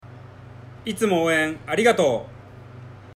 選手ボイス